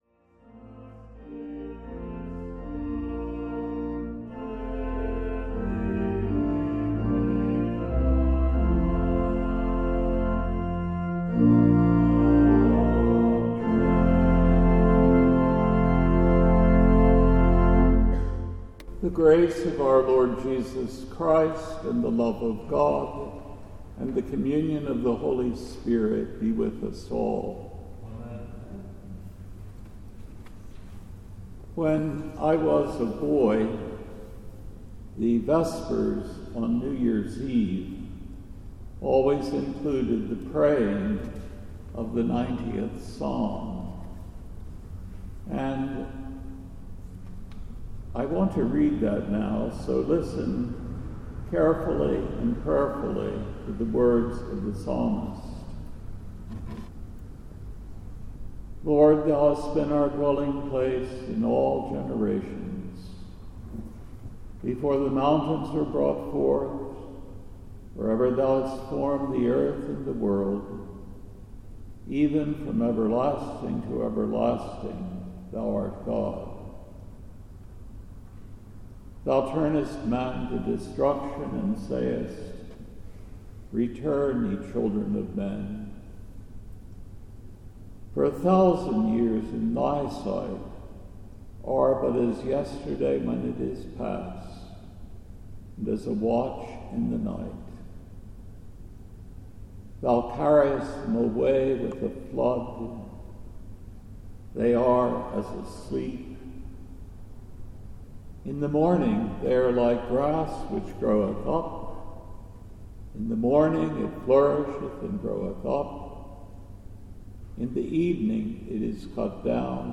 CLM-Eve-of-Circumcision-and-Name-of-Jesus-2024.mp3